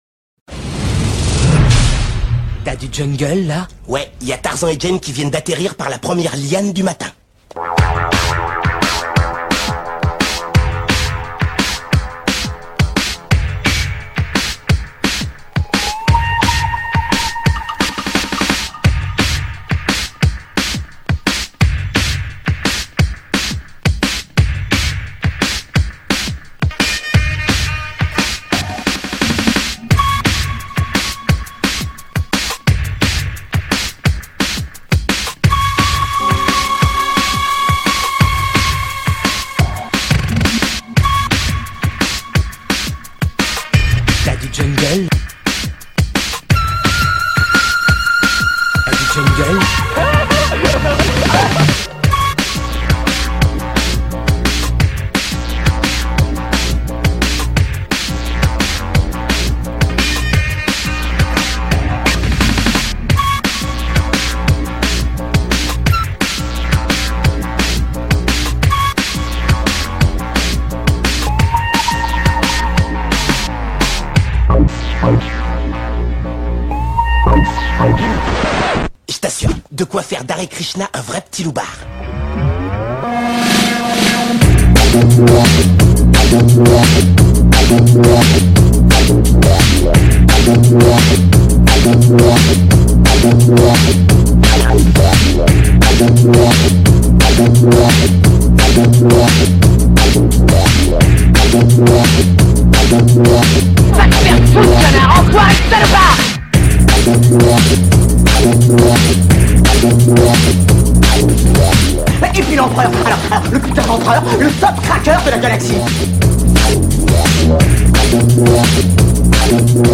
Laut !!!! :`( alles klar nichts eingereicht nice Laut !!!! :`( alles klar nichts eingereicht …